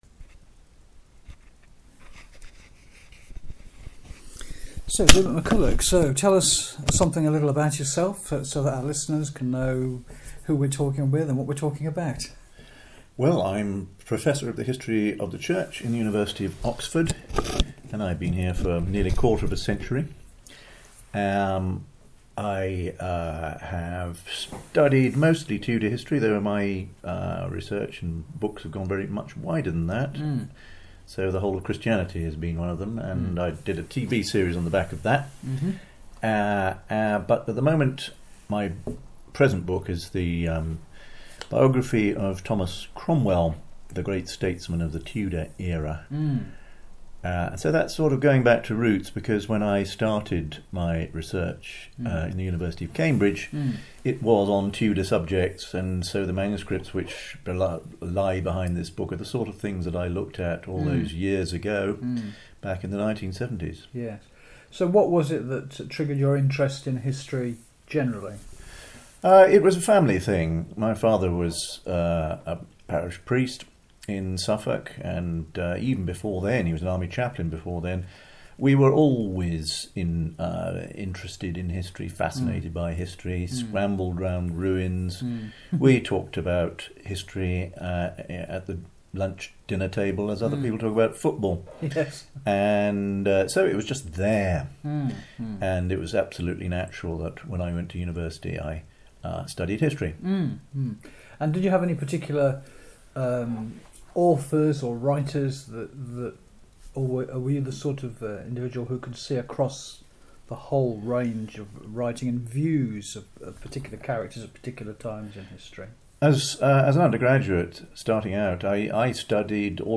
The “In The Cooler” interview will appear here Friday 2nd November broadcast.